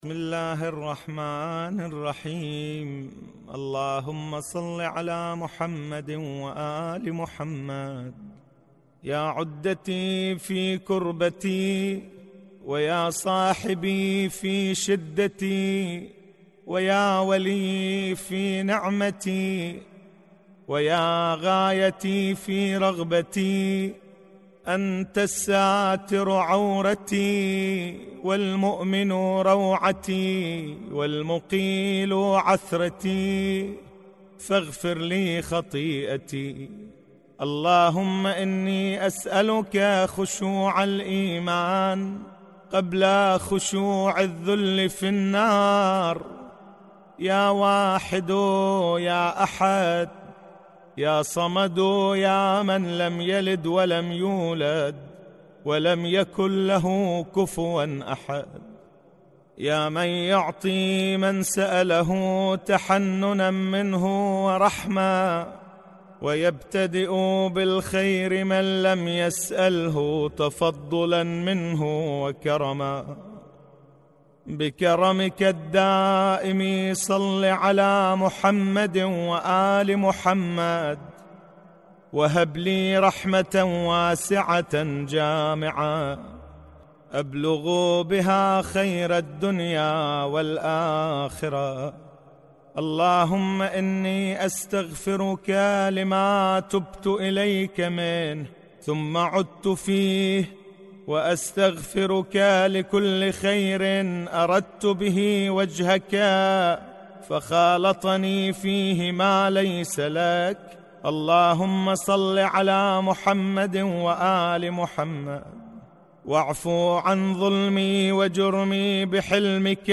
دعاء ياعدتي